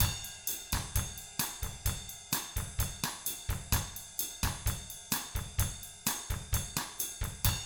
129BOSSAT2-L.wav